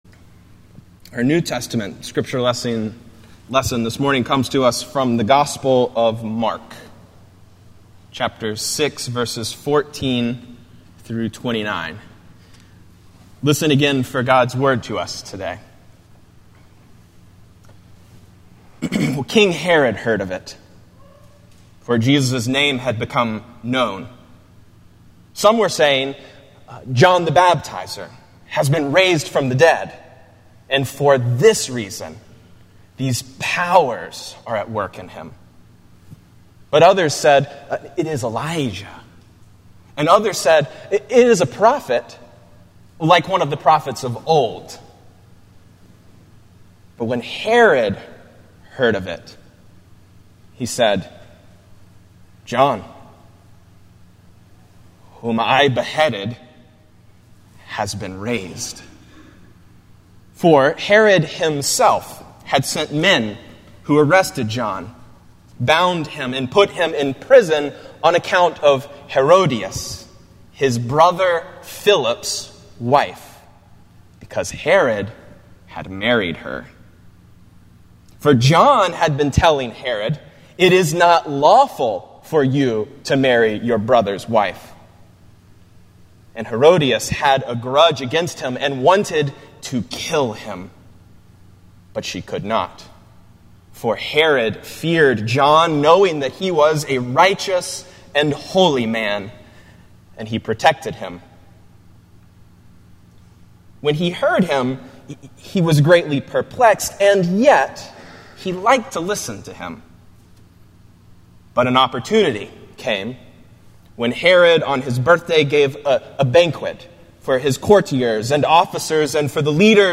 Morningside Presbyterian Church - Atlanta, GA: Sermons: #2 Not in My House